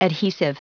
Prononciation du mot adhesive en anglais (fichier audio)
Prononciation du mot : adhesive